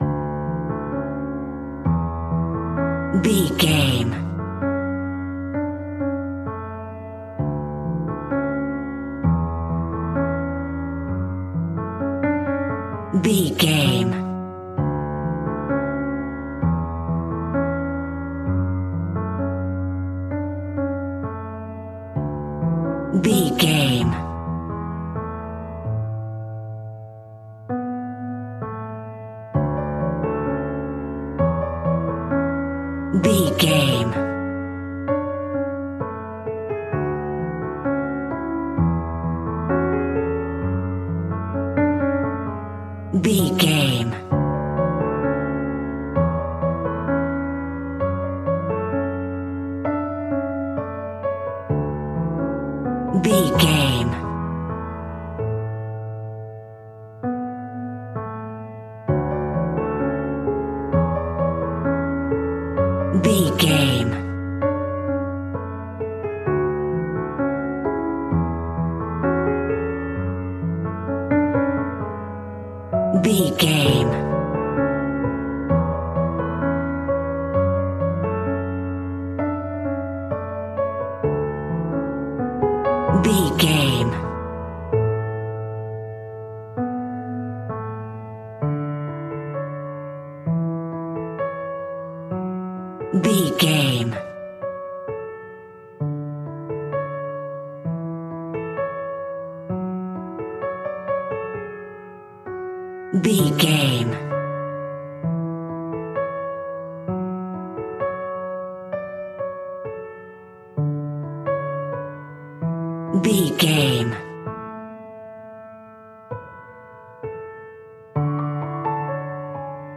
A glistening stunning piece of classical piano music.
Regal and romantic, a classy piece of classical music.
Ionian/Major
soft